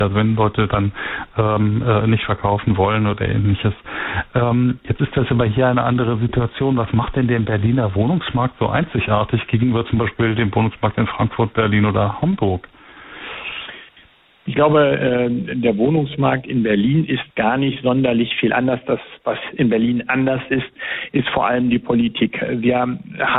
pta2021_10_06_bandbreite_begrenzung.mp3
Air Check